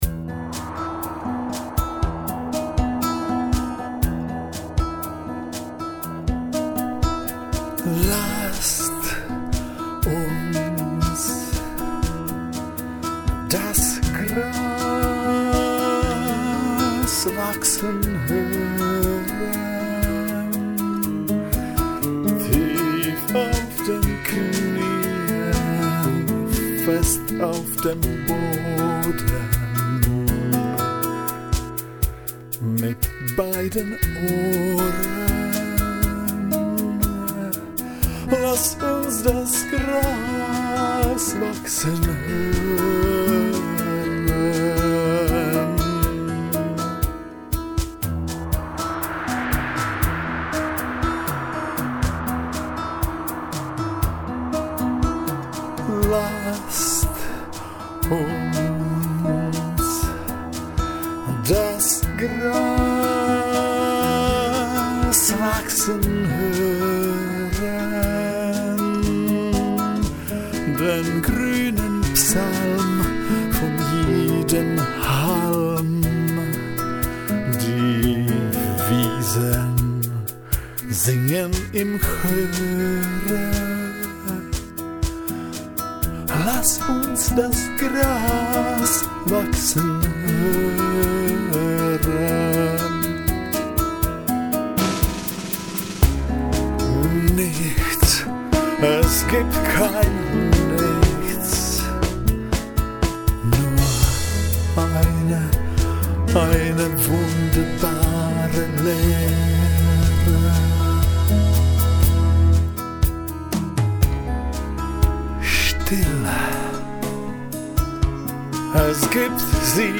...eine Interaktive Klanginstalation
OUTSIDE 3 - Kunstaustellung
grassinstr6pkf.mp3